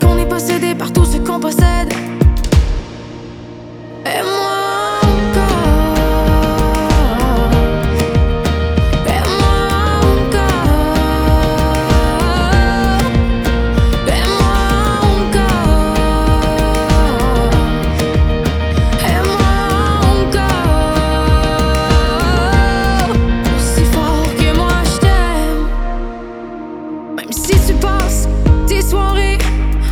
• Musique francophone